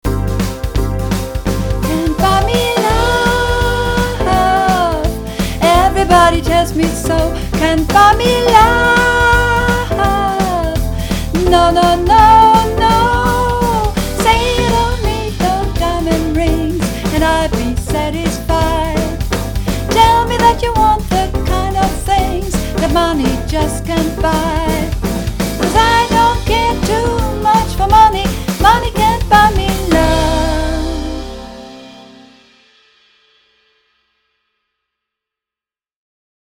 Übungsaufnahmen - Can't Buy Me Love
Can't Buy Me Love (Sopran)